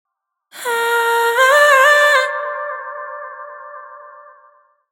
Her warm, deep, and soulful vocals bring profound emotion to everything they touch.
• 57 Unique vocal adlibs (Oohs and Aaahs) – dry and wet.